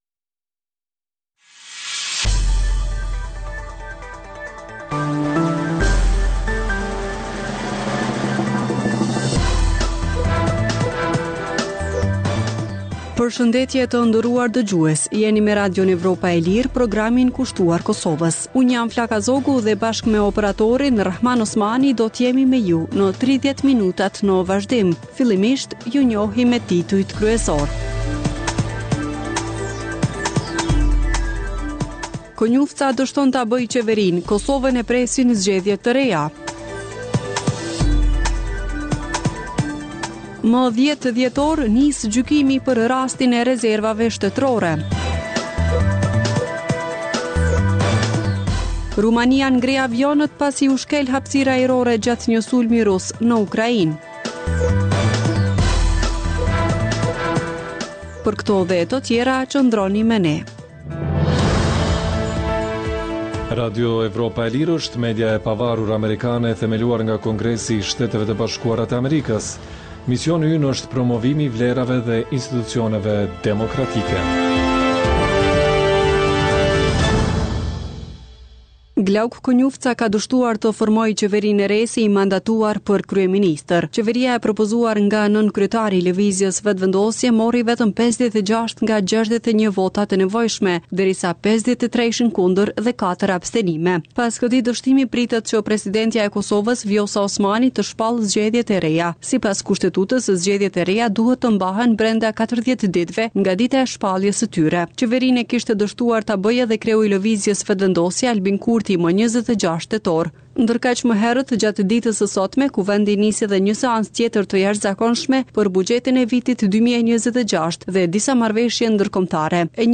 Emisioni i orës 16:00 është rrumbullakësim i zhvillimeve ditore në Kosovë, rajon dhe botë. Rëndom fillon me buletinin e lajmeve dhe vazhdon me kronikat për zhvillimet kryesore të ditës. Në këtë edicion sjellim edhe intervista me analistë vendës dhe ndërkombëtarë.